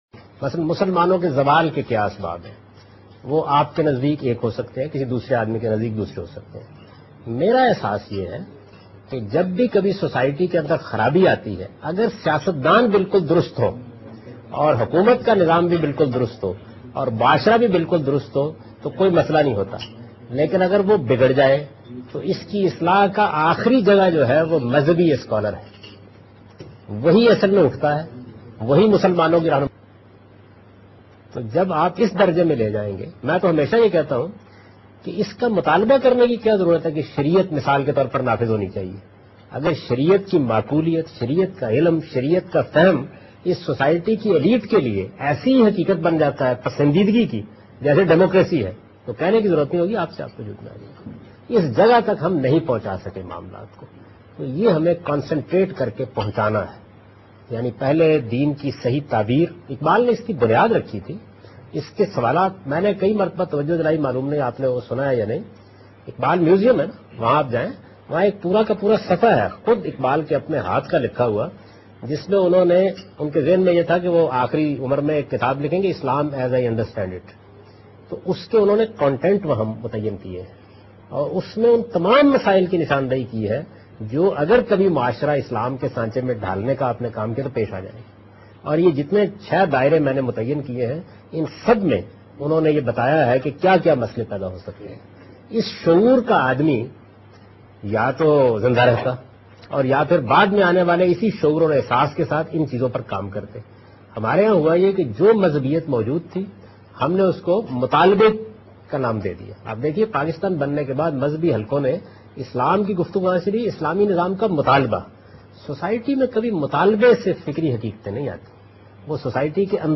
جاوید احمد غامدی اس پروگرام میں پاکستان میں اسلام کے نفاز کے متعلق گفتگو کر رہے ہیں